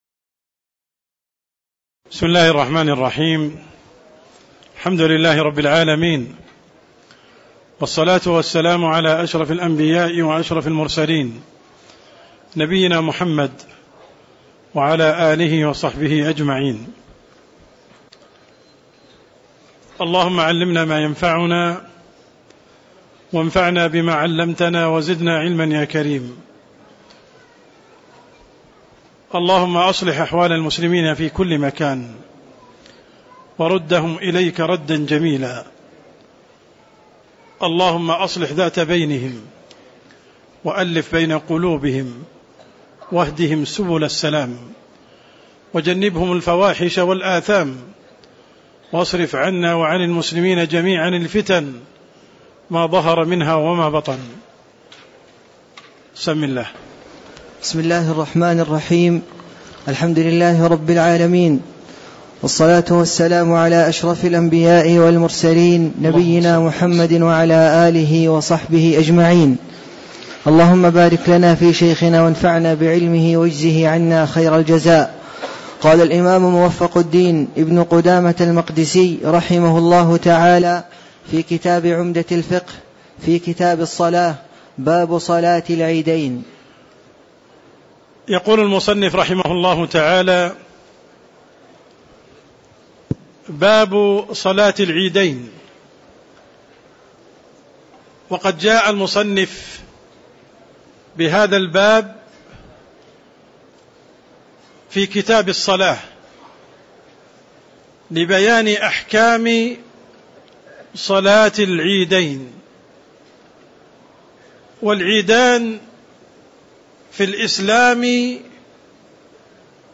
تاريخ النشر ٢٤ شعبان ١٤٣٧ هـ المكان: المسجد النبوي الشيخ: عبدالرحمن السند عبدالرحمن السند باب صلاة العيدين (19)